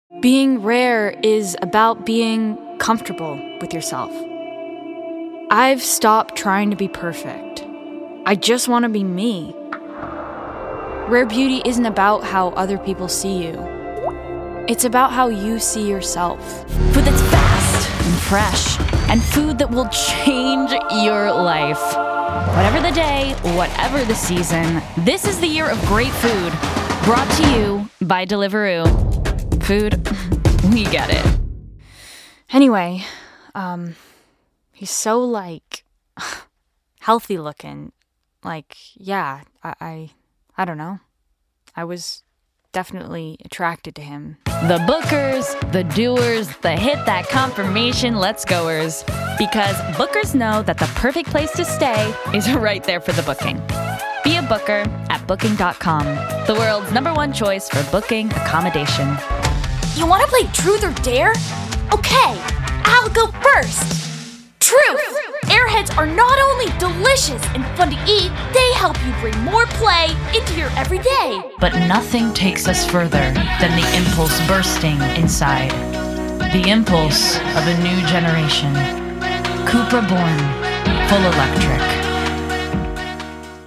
Description: American: vibrant, fresh, engaging
Age range: 20s - 30s
Commercial 0:00 / 0:00
American*, Californian, West Coast